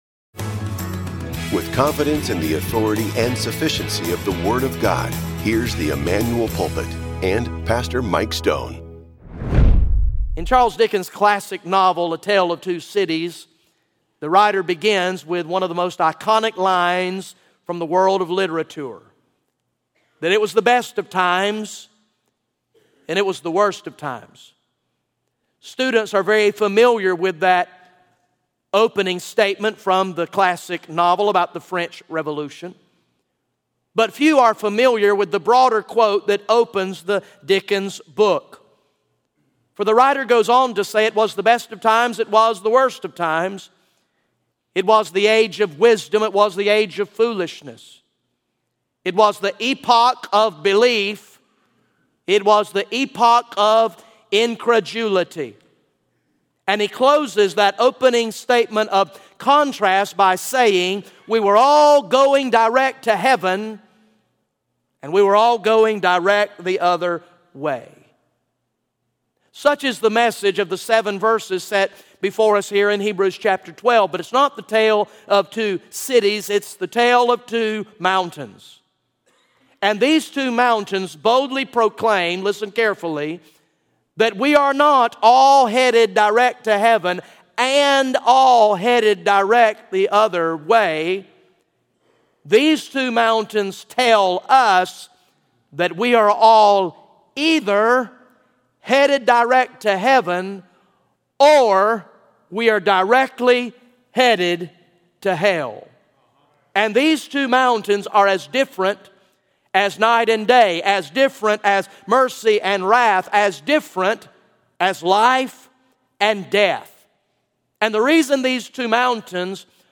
GA Message #53 from the sermon series through the book of Hebrews entitled “The Supremacy of Christ”